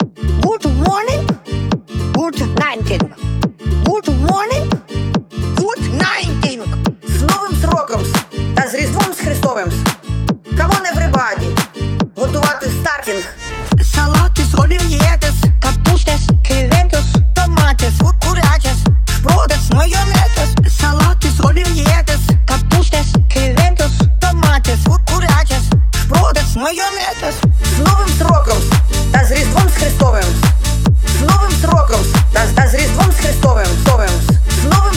Жанр: Танцевальные / Украинский рок / Украинские
# Dance